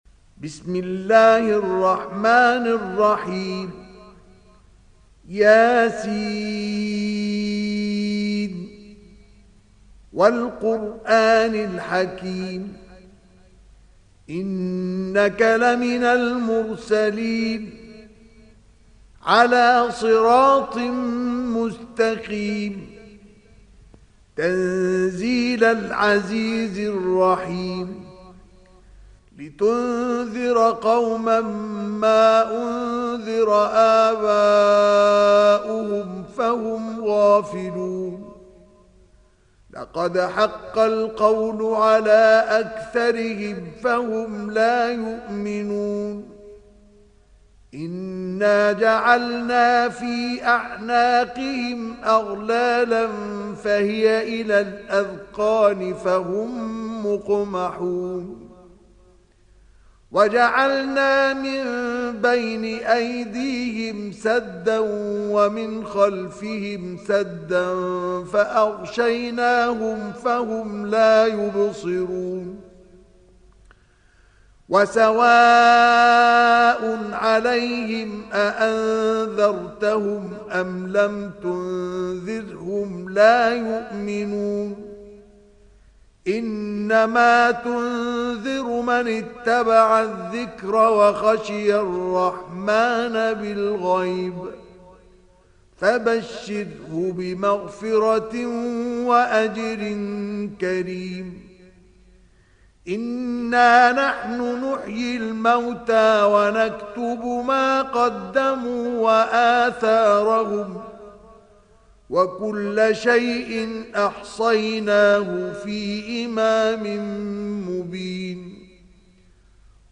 تحميل سورة يس mp3 بصوت مصطفى إسماعيل برواية حفص عن عاصم, تحميل استماع القرآن الكريم على الجوال mp3 كاملا بروابط مباشرة وسريعة